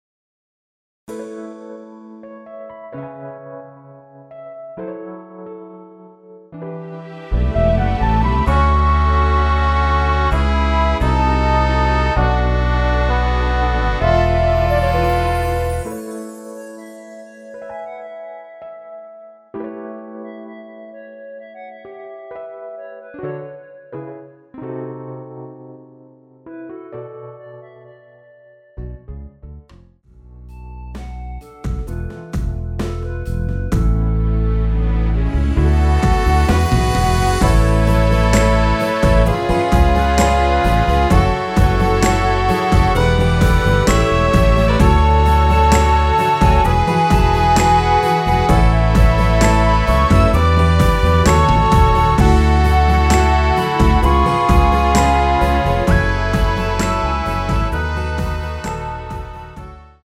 원키에서(+6)올린 멜로디 포함된 MR입니다.(미리듣기 참조)
앞부분30초, 뒷부분30초씩 편집해서 올려 드리고 있습니다.
중간에 음이 끈어지고 다시 나오는 이유는